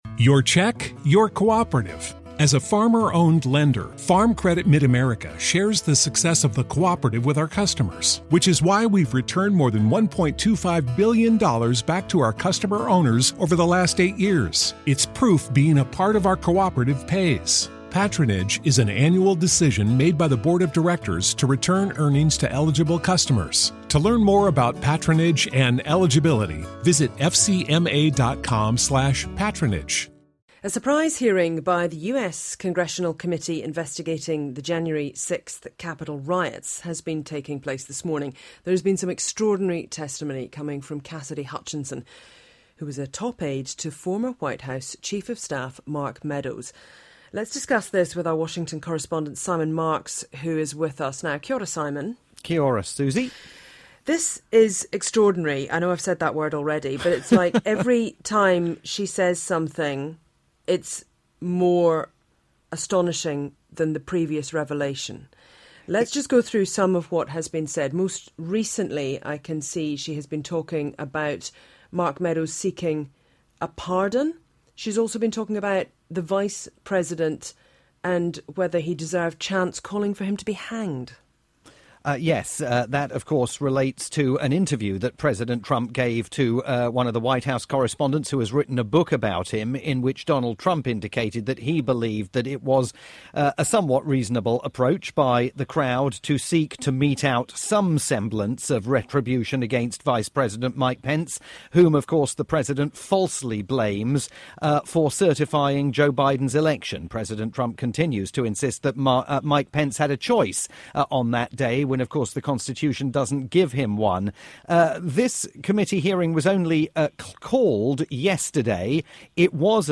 live update for Radio New Zealand's "Morning Report"